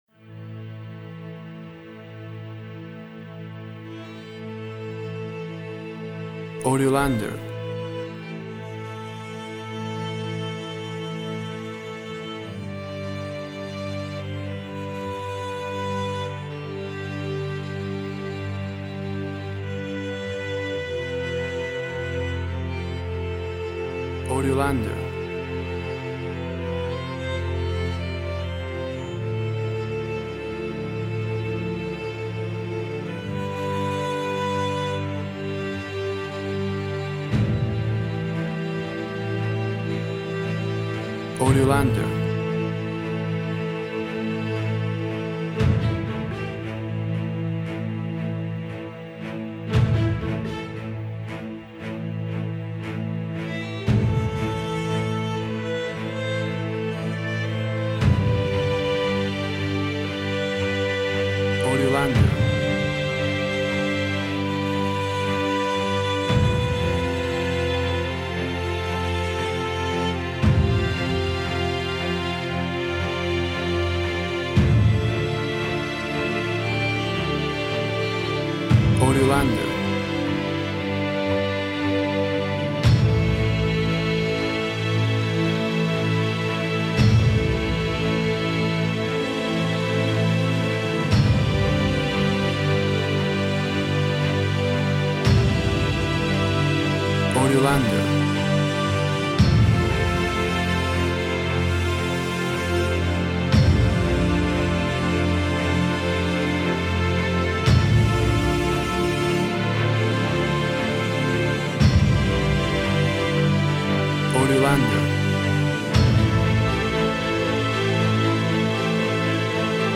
Music with epic and cinematic orchestral sounds.
Tempo (BPM) 115